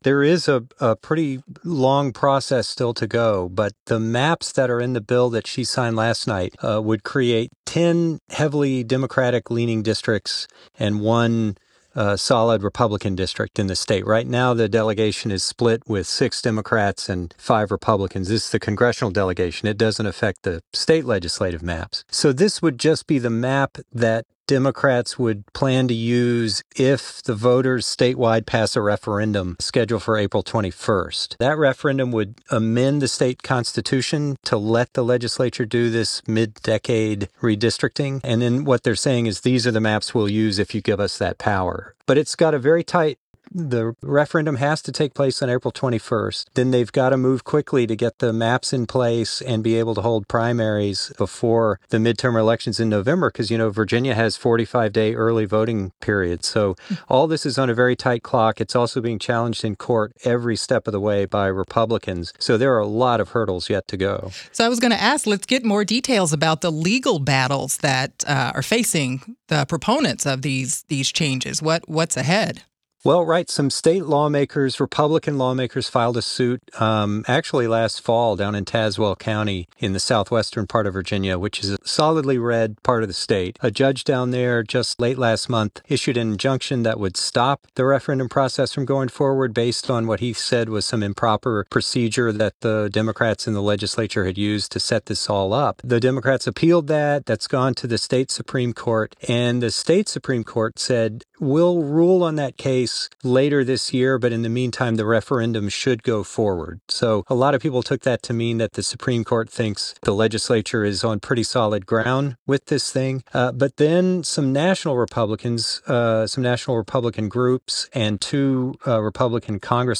joined WTOP to discuss Virginia Gov. Abigail Spanberger signing a bill that could give Democrats more seats in Congress.